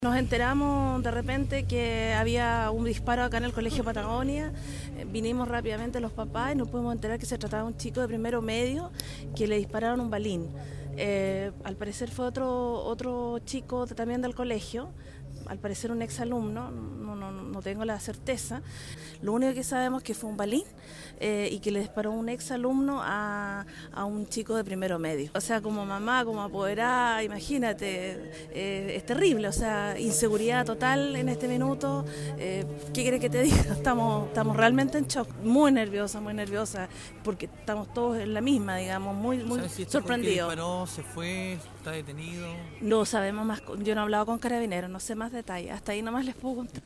27-APODERADA.mp3